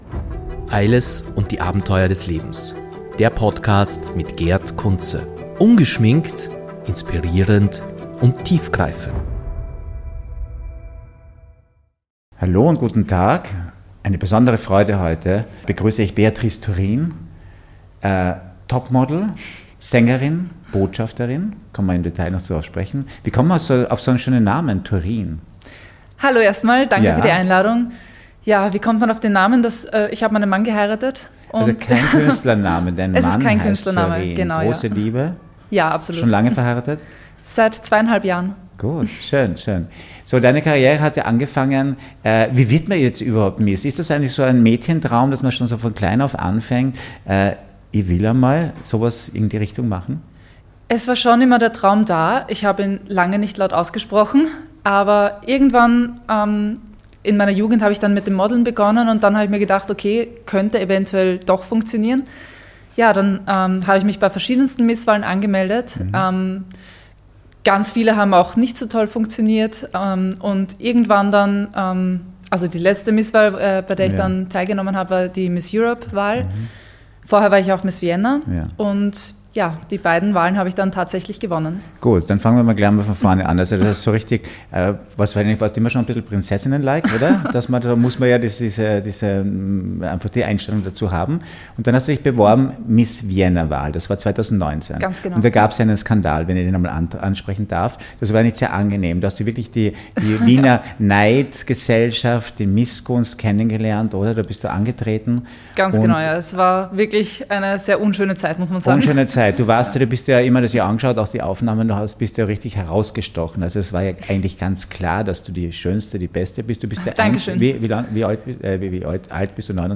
Ein bewegendes Gespräch über Resilienz, Kreativität und die Kraft, sich selbst treu zu bleiben.